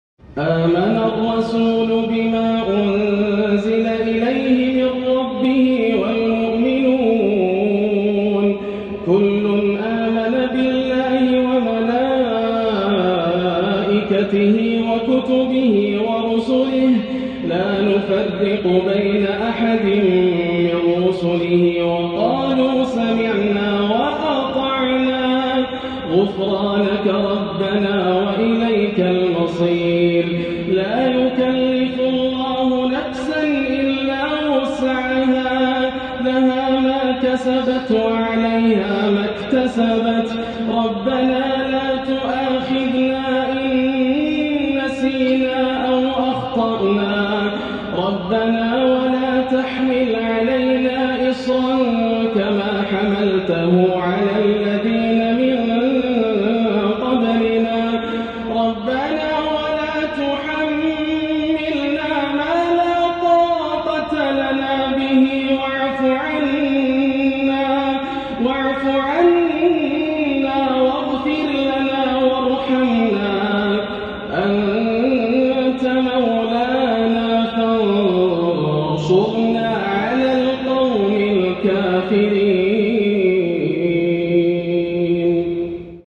Last 2 Ayat of Surah Baqarah by Yasser Al Dosari | Beautiful Quran Recitation
Surah Al Baqarah 2:285-286 🗓 Maghrib, Oct 18, 2019 / Safar 19, 1441 📍 Masjid Al Haram, Makkah, Saudi Arabia